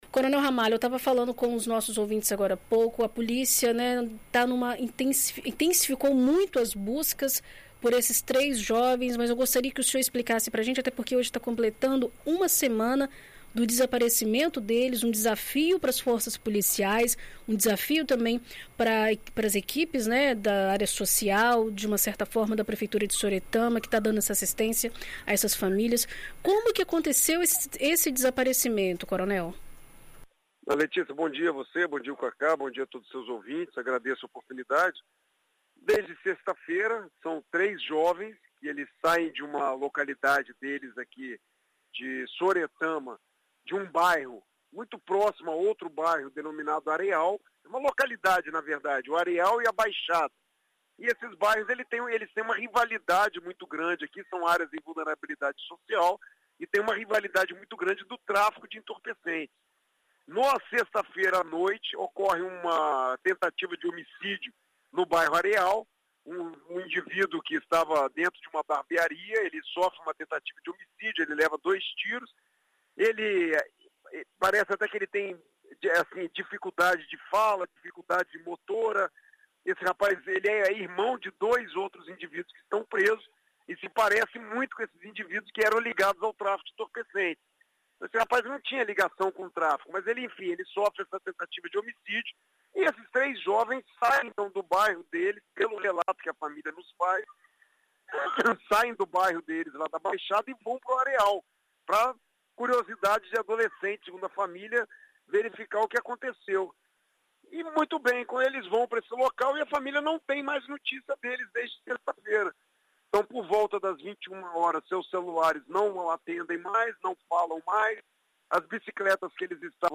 Em entrevista a BandNews FM ES nesta sexta-feira (25), o secretário de segurança pública, Coronel Alexandre Ramalho fala sobre o caso.